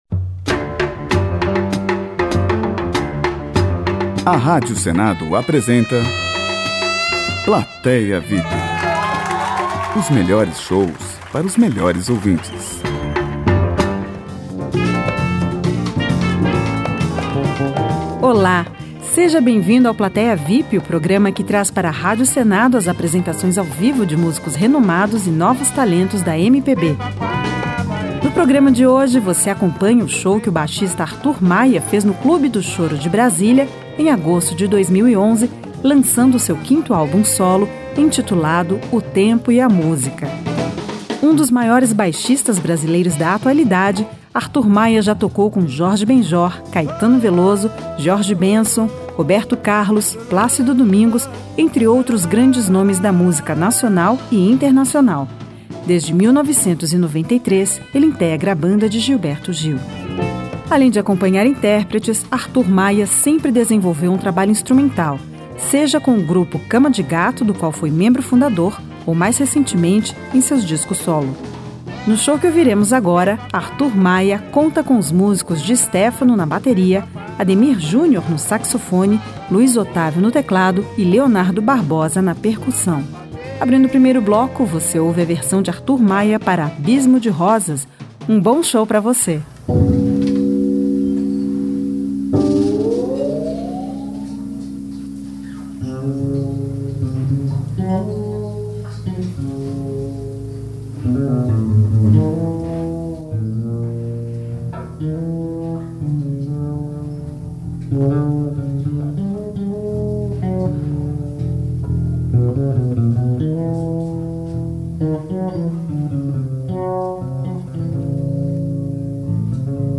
Música Brasileira